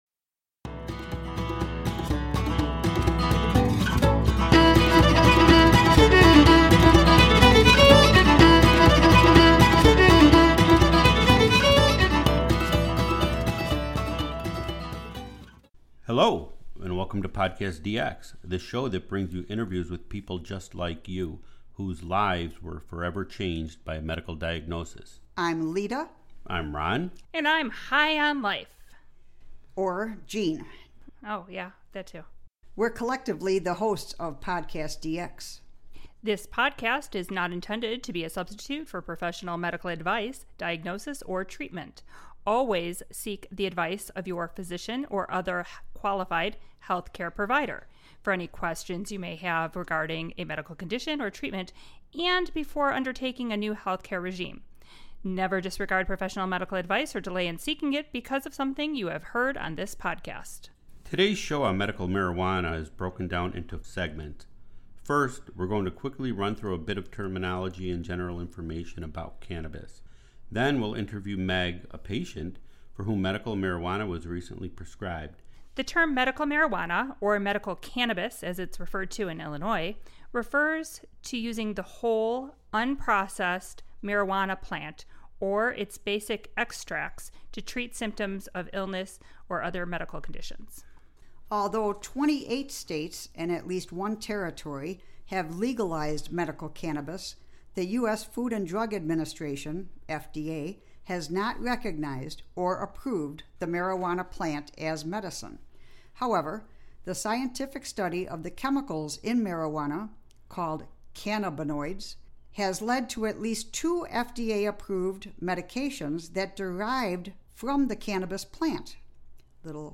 In this episode we are first have a forum discussion covering the terms used in medical cannabis. The PodcastDX co-hosts will talk briefly about the terminology to familiarize you with some of the terms.